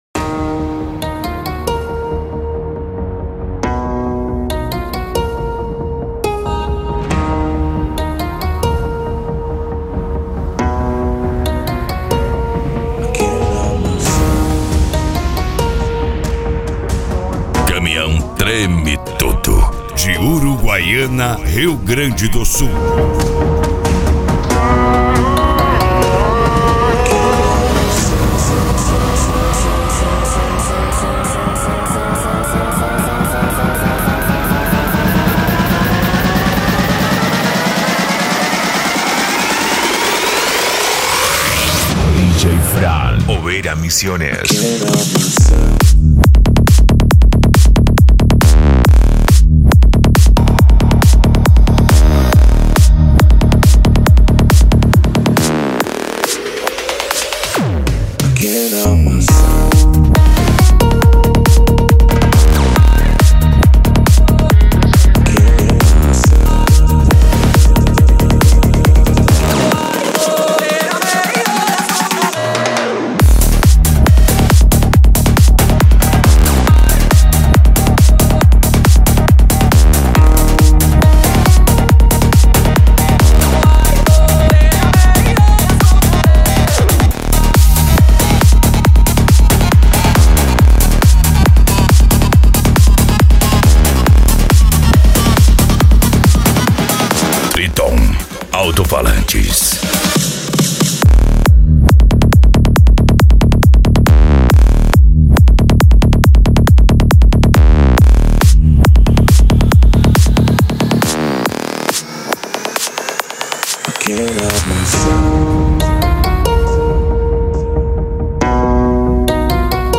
Modao
PANCADÃO
Remix